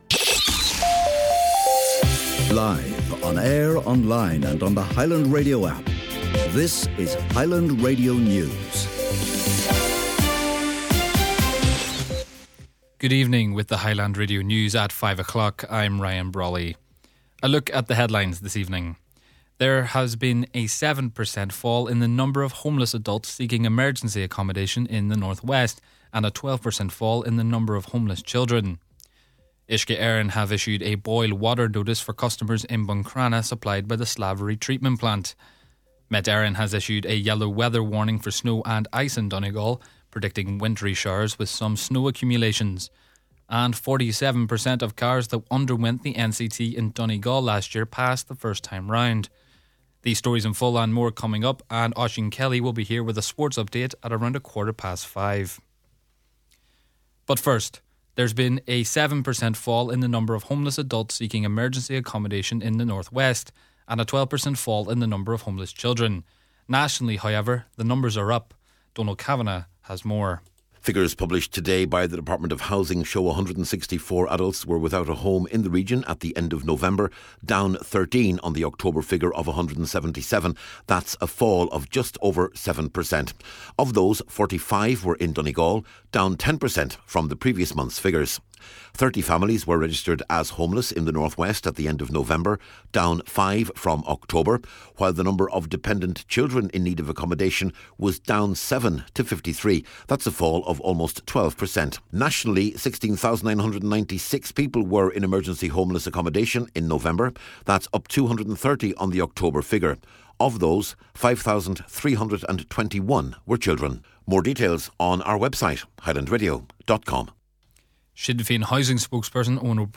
Main Evening News, Sport, an Nuacht and Obituary Notices – Friday January 2nd